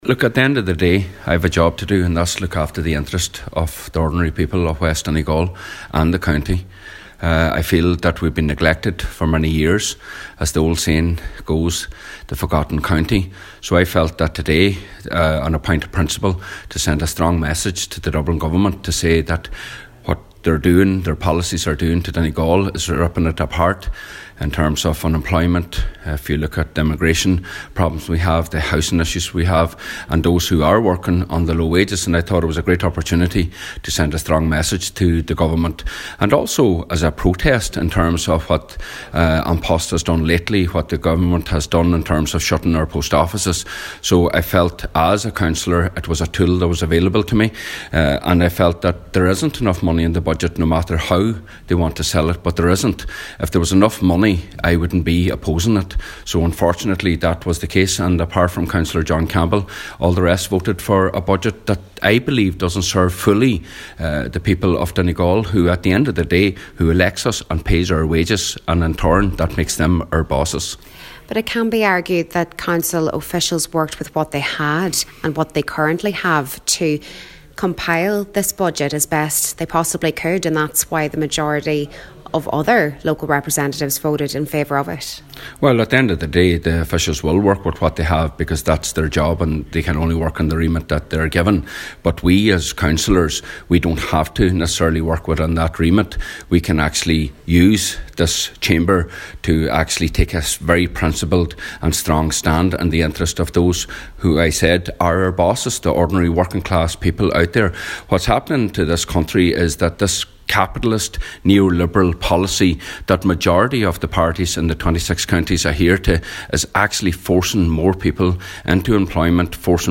Speaking last night, Cllr Mac Giolla Easbuig says there have been too many cuts to rural services and the budget will do nothing to address a range of serious issues in Donegal: